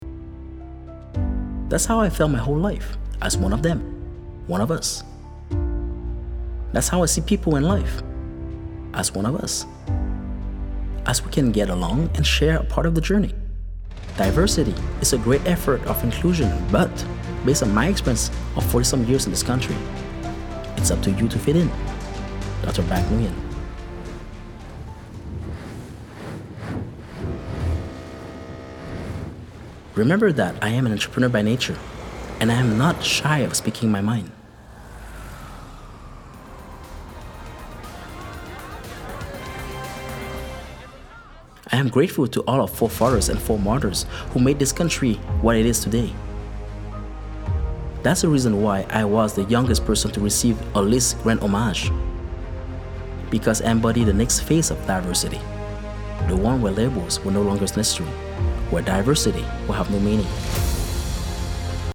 UAX is the blockbuster of the Audiobooks.